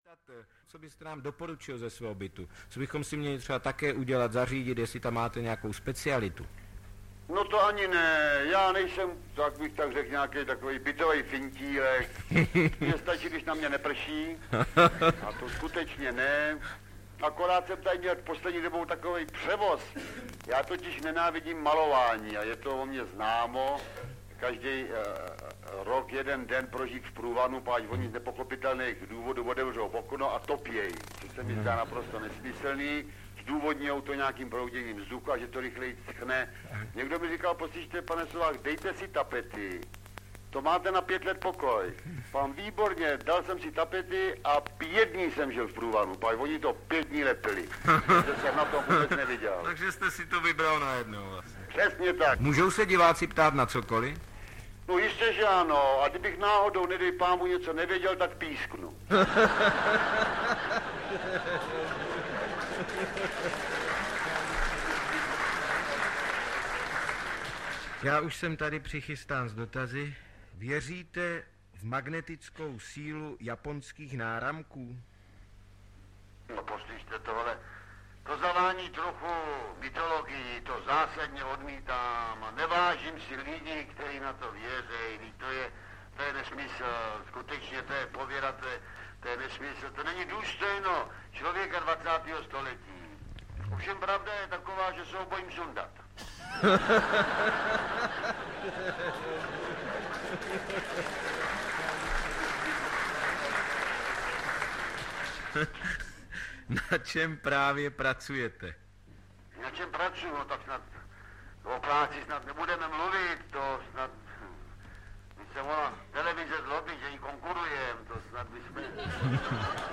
S pozdravem Jiří Sovák audiokniha
Audiokniha S pozdravem Jiří Sovák obsahuje výběr z vystoupení Jiřího Sováka.
Ukázka z knihy